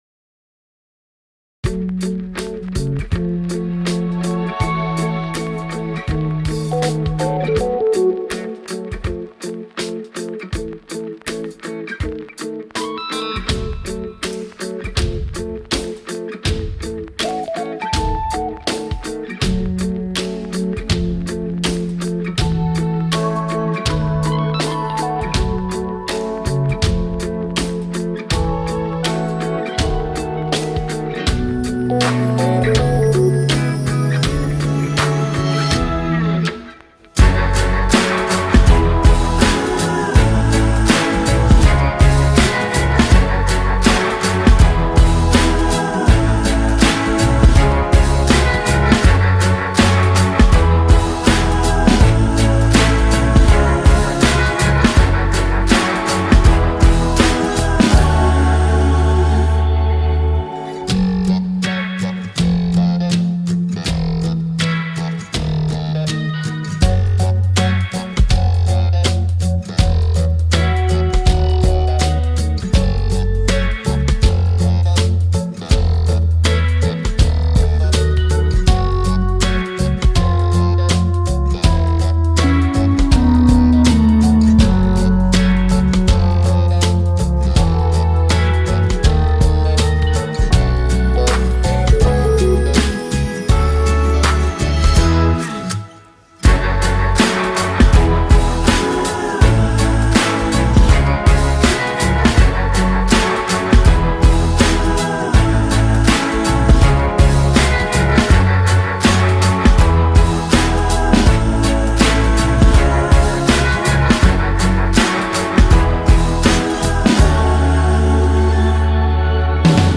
mutlu huzurlu rahatlatıcı fon müziği.